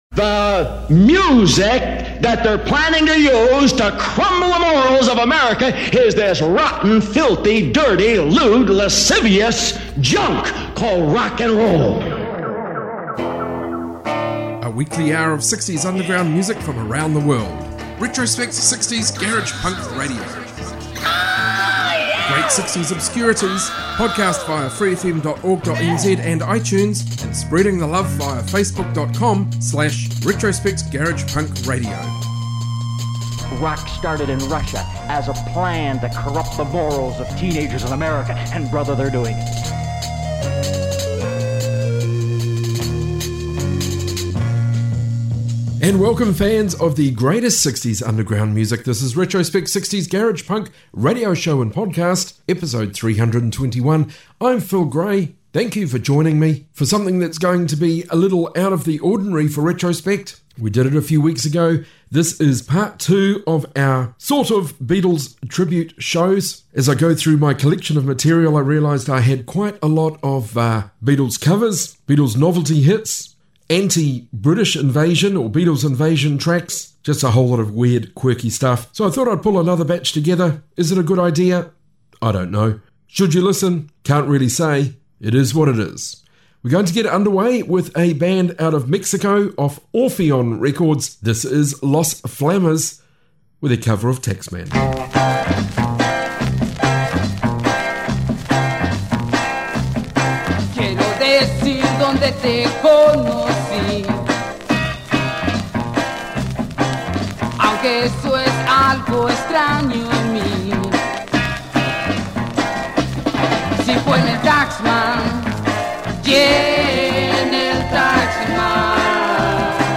60s global garage rock podcast